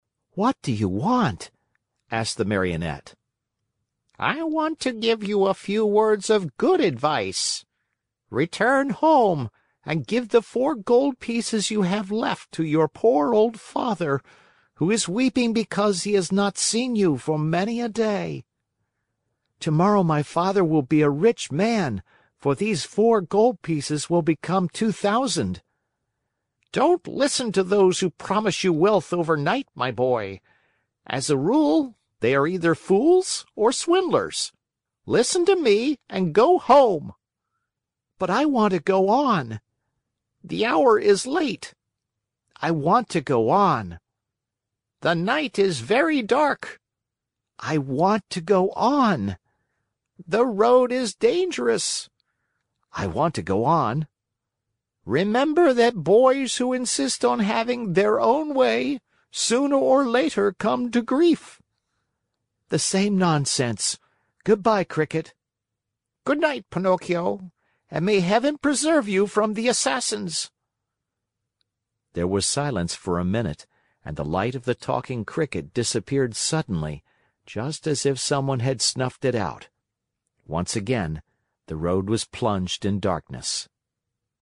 在线英语听力室木偶奇遇记 第31期:不听劝告的听力文件下载,《木偶奇遇记》是双语童话故事的有声读物，包含中英字幕以及英语听力MP3,是听故事学英语的极好素材。